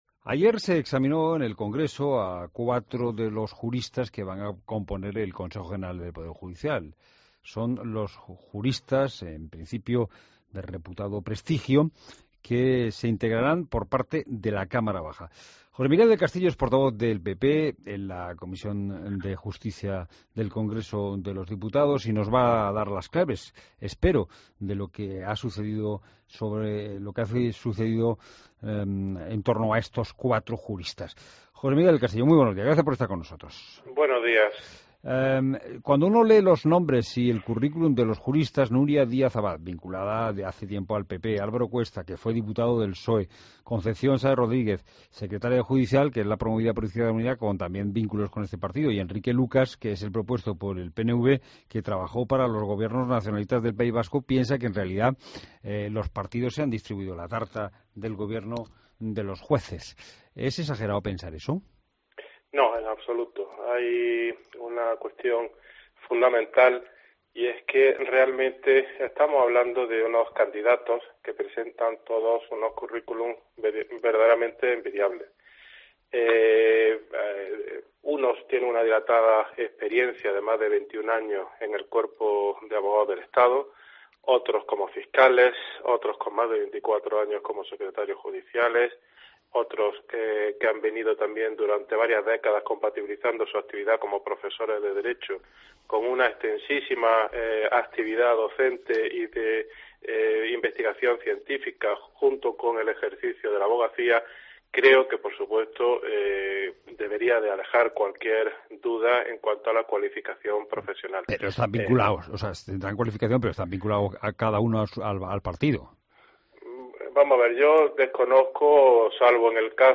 Entrevista a José Miguel del Castillo, Portavoz del PP en Comisión de Justicia